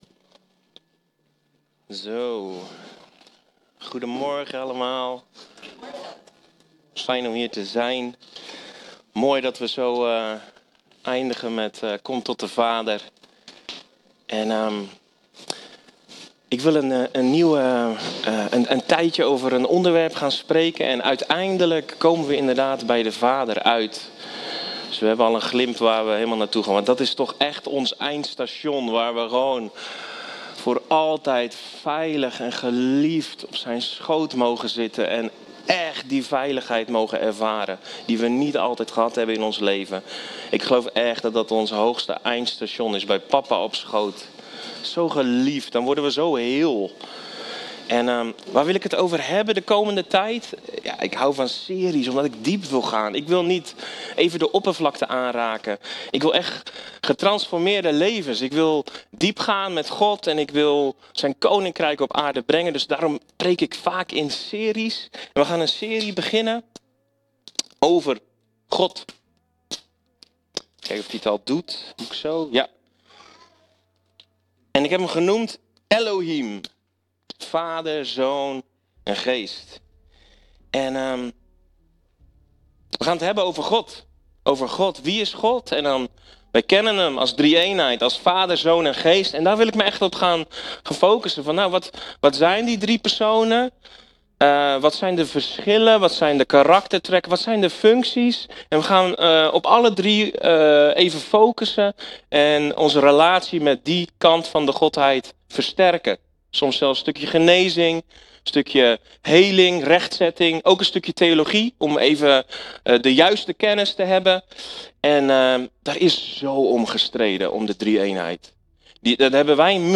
Elohim – Vader, Zoon en Geest | Baptistengemeente Tiel